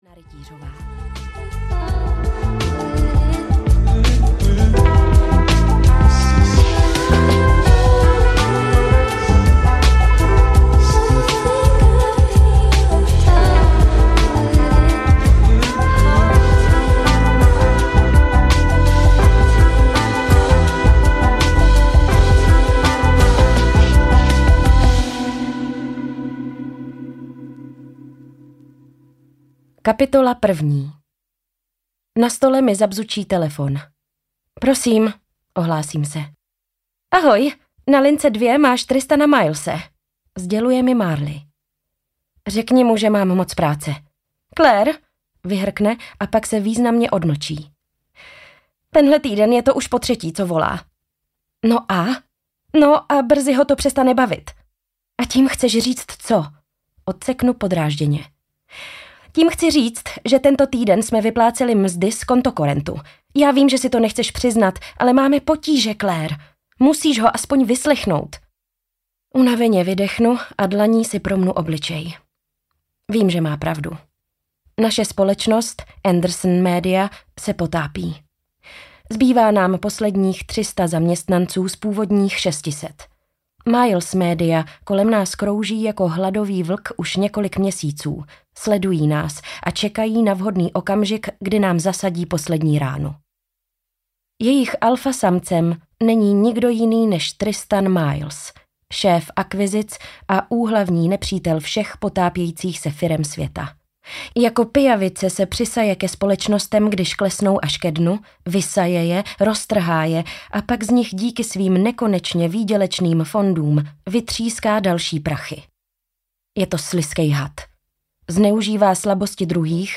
Na druhý pokus audiokniha
Ukázka z knihy